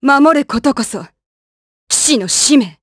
voices / heroes / jp
Glenwys-Vox_Victory_jp.wav